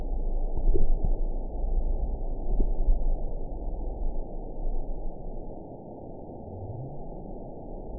event 922638 date 02/13/25 time 23:44:35 GMT (2 months, 2 weeks ago) score 9.57 location TSS-AB10 detected by nrw target species NRW annotations +NRW Spectrogram: Frequency (kHz) vs. Time (s) audio not available .wav